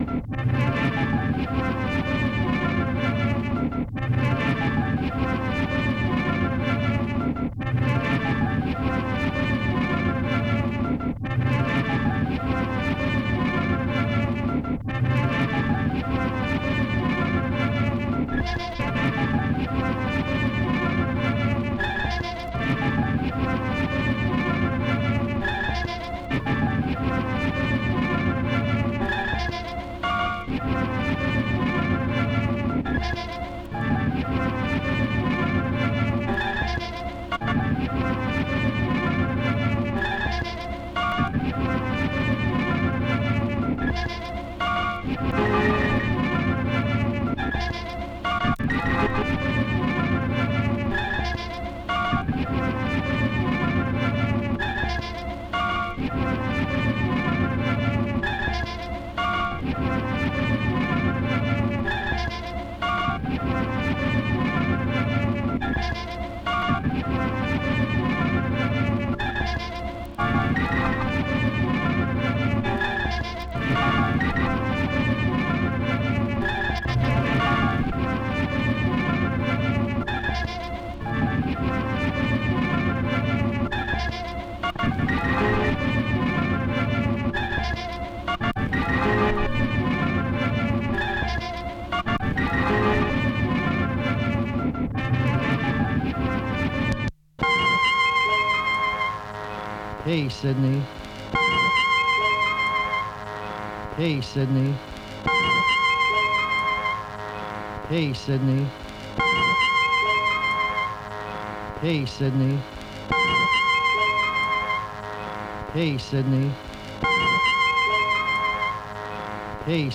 Uher 4200, scissors, unspooled tape, Oto Machines BIM